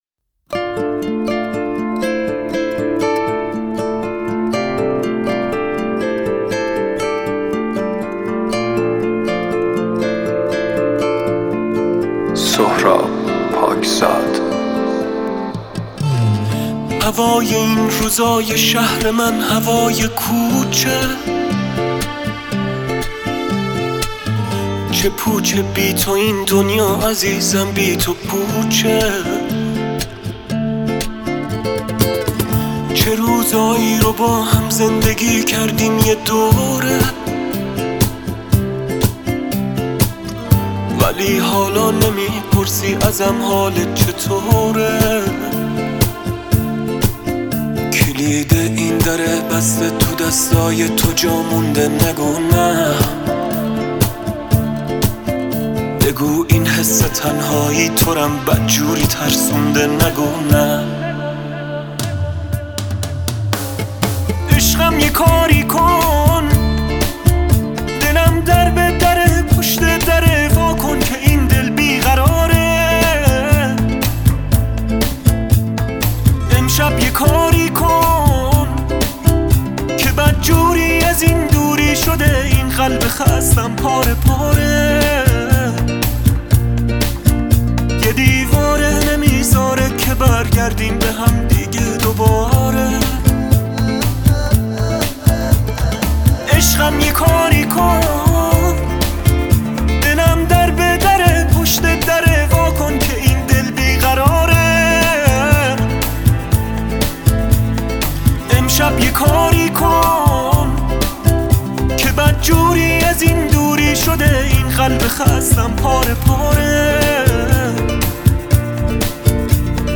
Unplugged Mix